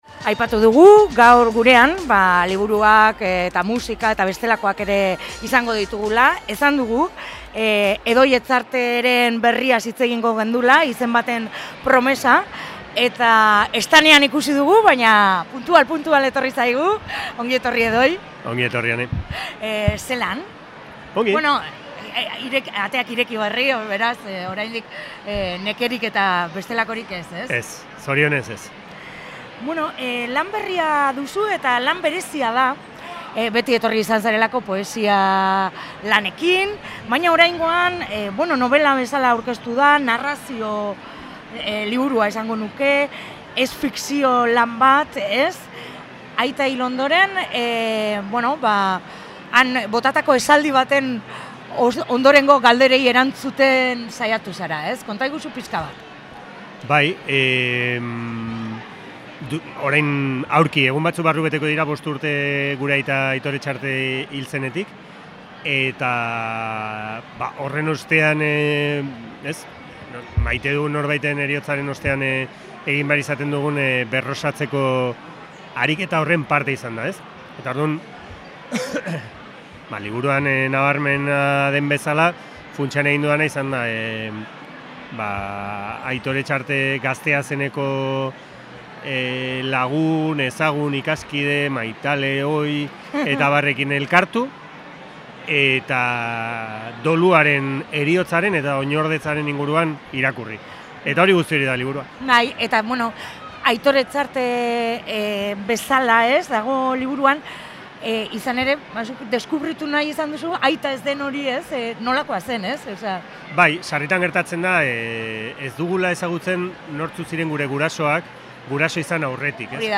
“Izen baten promesa” eleberriaz solasean